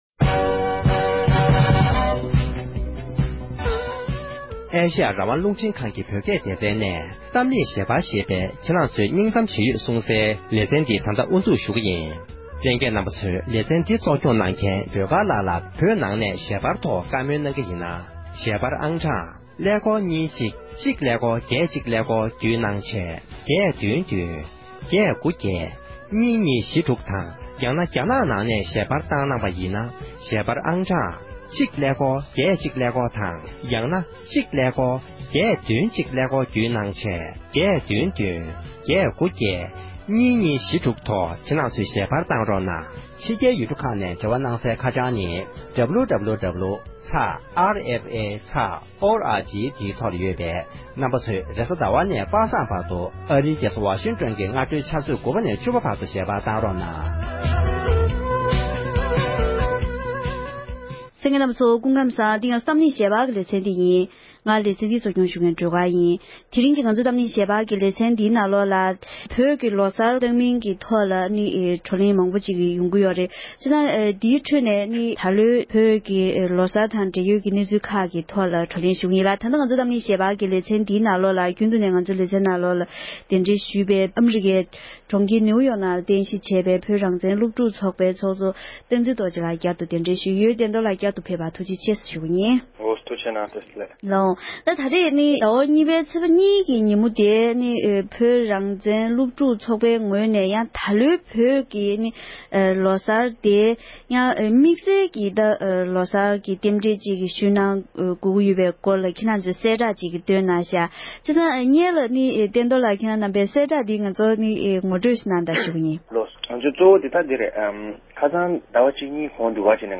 ལོ་གསར་གཏོང་མིན་ཐད་བགྲོ་གླེང་།